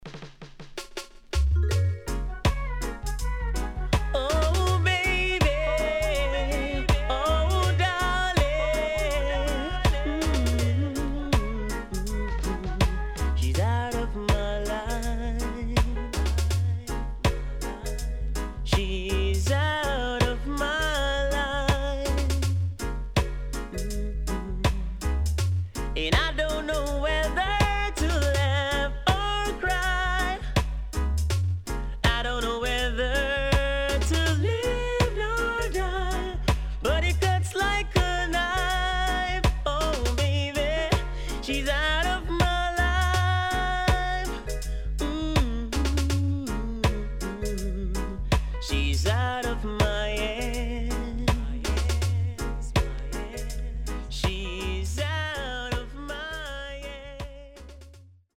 HOME > LP [DANCEHALL]
SIDE A:少しチリノイズ入りますが良好です。盤面は所々うすいこまかい傷あり。